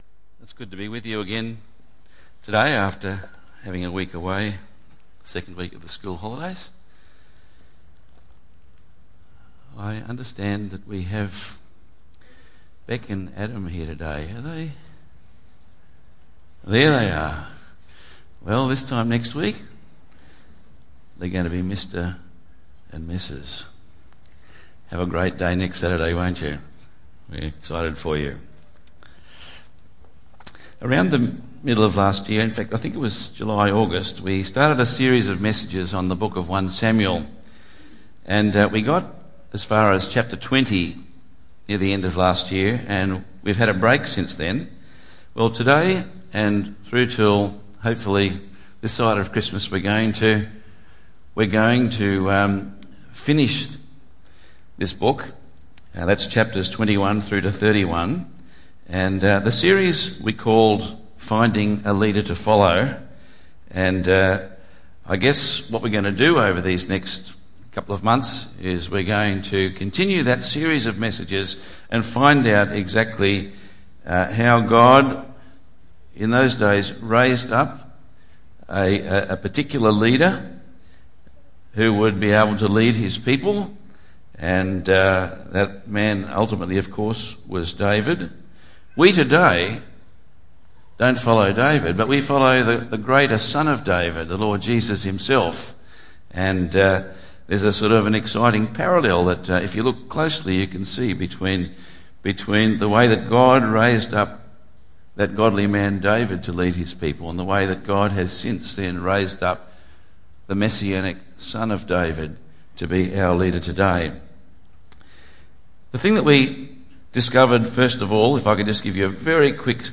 He also provides the perfect leader for us to follow in David’s descendant, Jesus Christ, who offers to save us from our greatest enemies, sin and death! 1 Samuel 21:1-22:23 Tagged with Sunday Morning